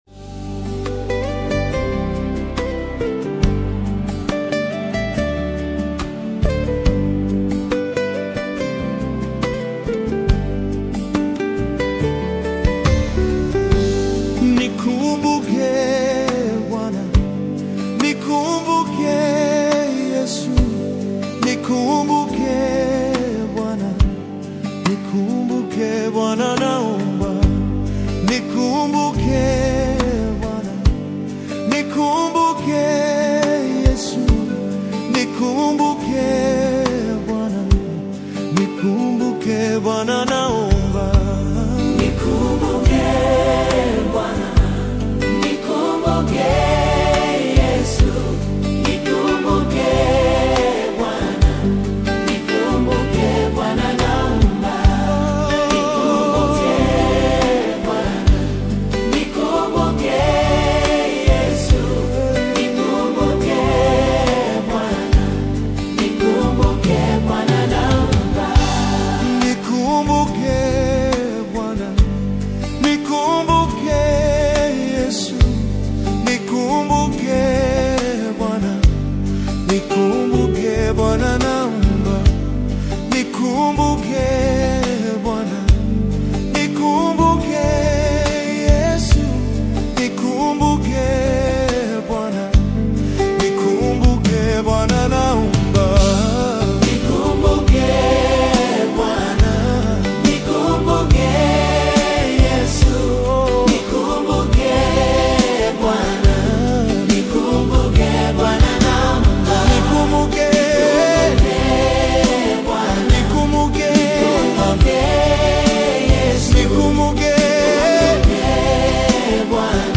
The soul-stirring and atmospheric track